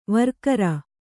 ♪ varkara